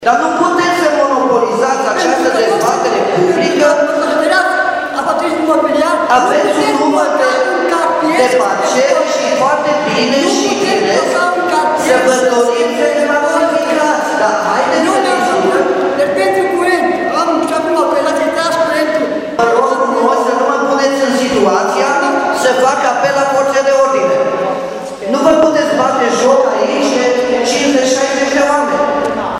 Un cetățean din cartierul Plopi a inistat pentru realizarea unor investiții în zonă. Discuțiile au degenerat, iar primarul primarul l-a acuzat pe bărbat că are interese personale și l-a amenințat cu evacuarea forțată din sala de dezbateri: